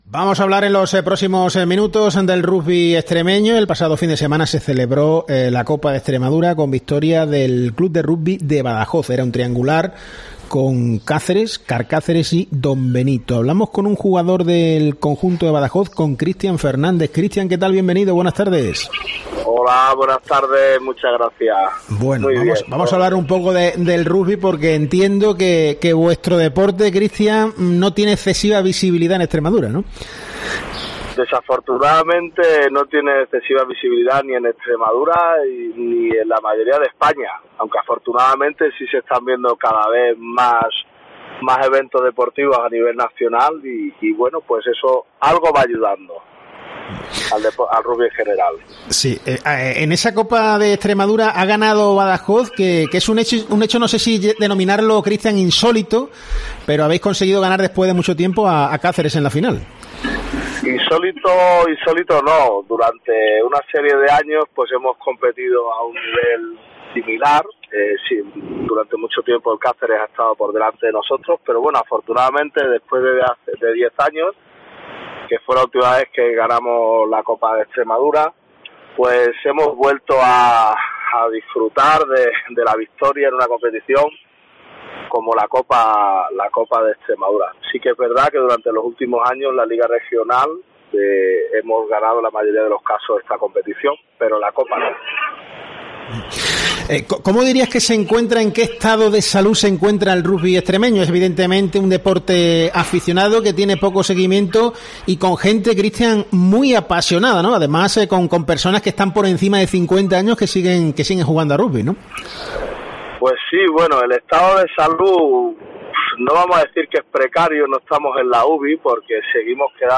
En COPE hemos hablado con el jugador